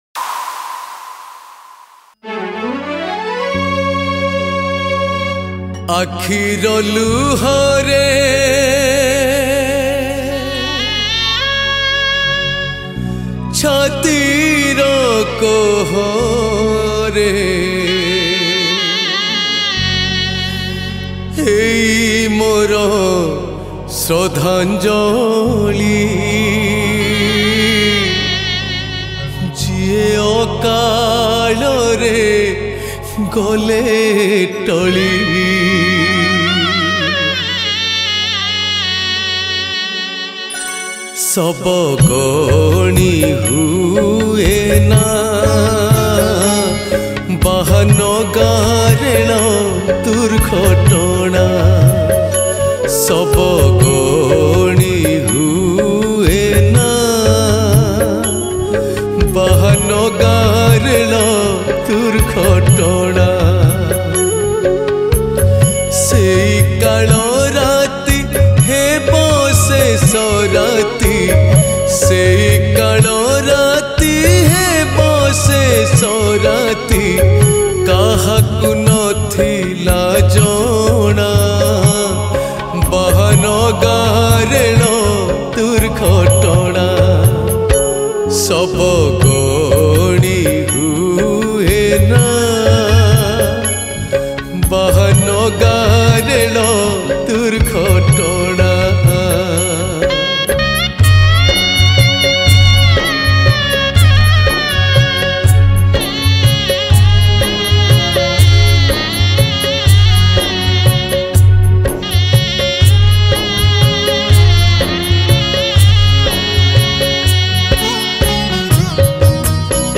Ratha Yatra Odia Bhajan 2023 Songs Download